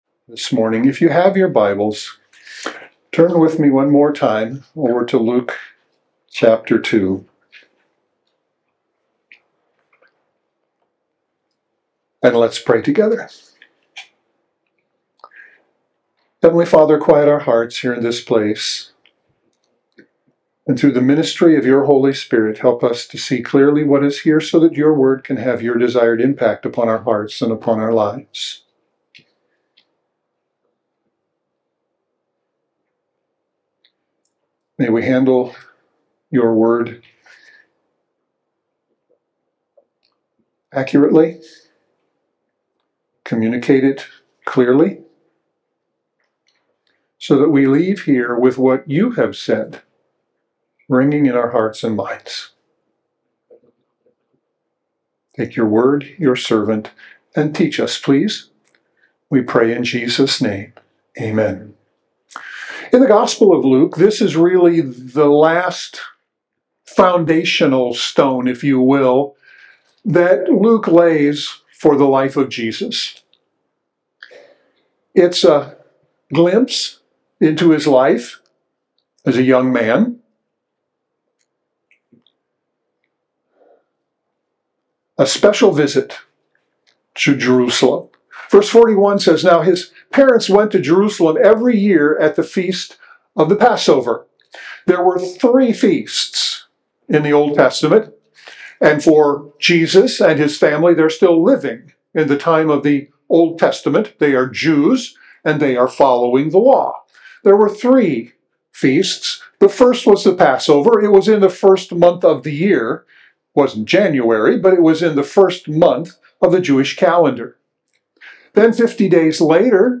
WLBC - Sermons